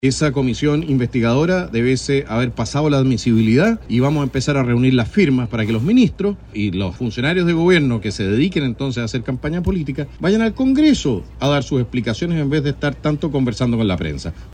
En medio de su gira en Arica, aseguró que el gobierno ha intervenido hace mucho tiempo en los procesos electorales y, debido a ello, solicitaron la creación de una comisión investigadora en el Congreso.